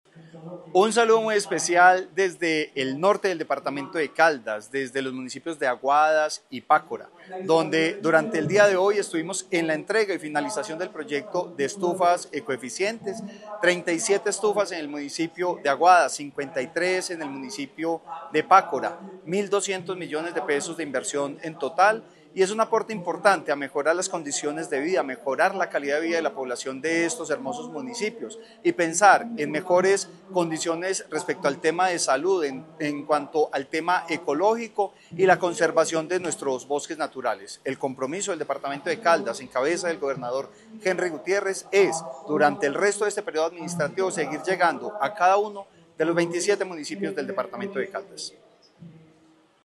Francisco Javier Vélez Quiroga, secretario de vivienda de Caldas.